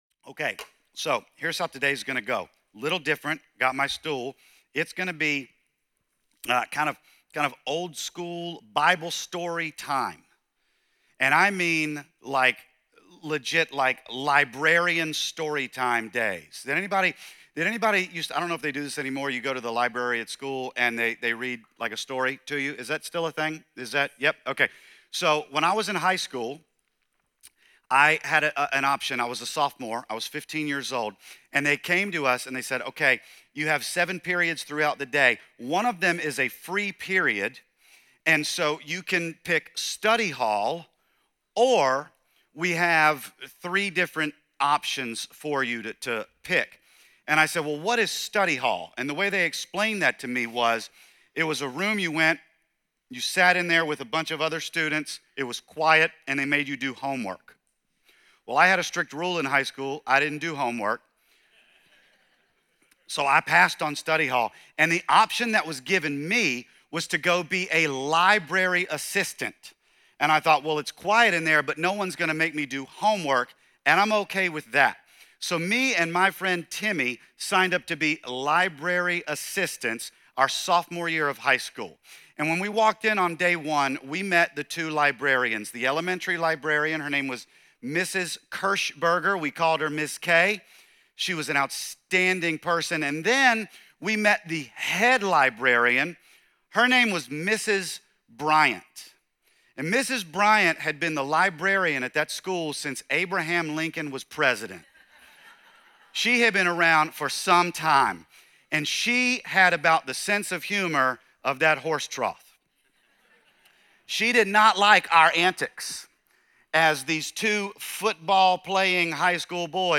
John 4:1-26 - Sonrise Church, Santee